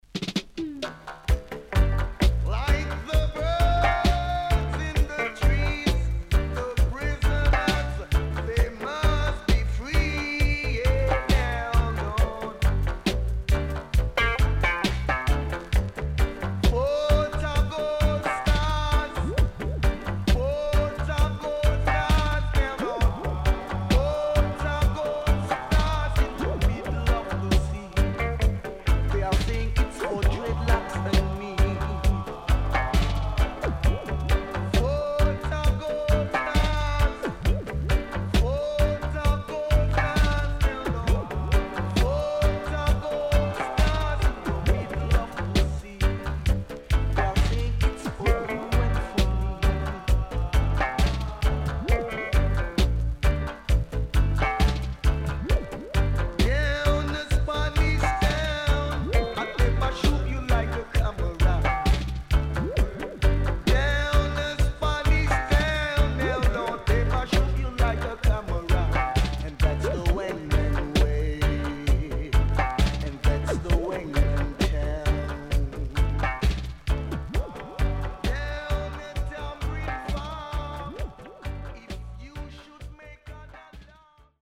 渋Vocal